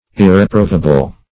Search Result for " irreprovable" : The Collaborative International Dictionary of English v.0.48: Irreprovable \Ir`re*prov"a*ble\, a. Incapable of being justly reproved; irreproachable; blameless; upright.